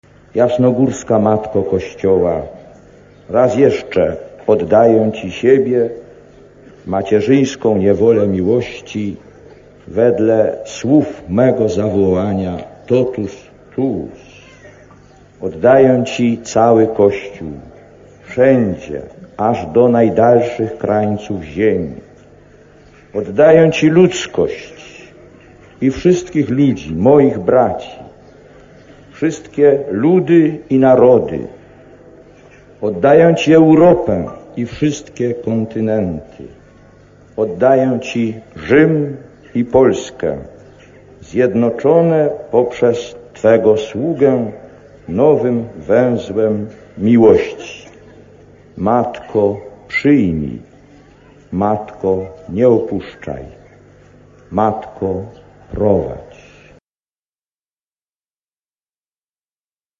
Lektor: Z przemówienia pożegnalnego na Jasnej Górze (6.06.1979